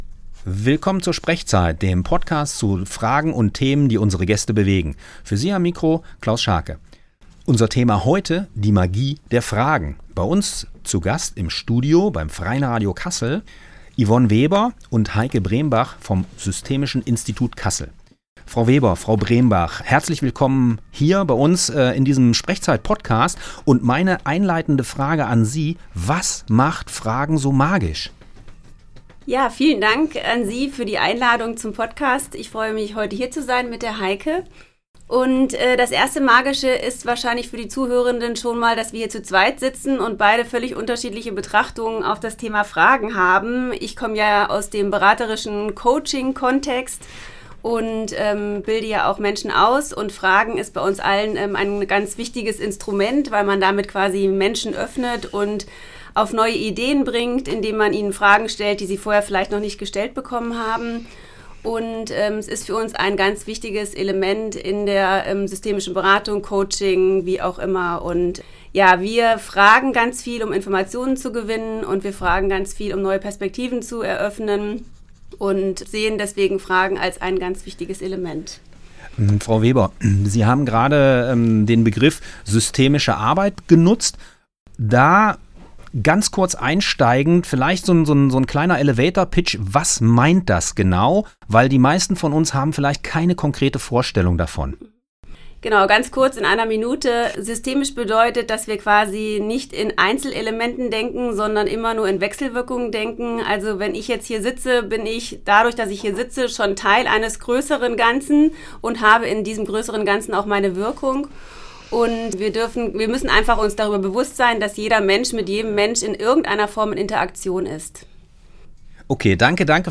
Interview
Dieser SprechZeit-Podcast wurde am 21.3.2025 beim Freien Radio Kassel aufgezeichnet. sprechzeit2025-03-21-magie-fragen